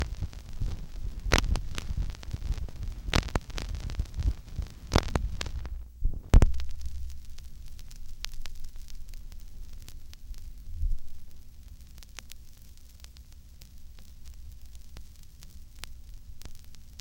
old_vinyl_record
dusty film old phono record scratch vinyl sound effect free sound royalty free Movies & TV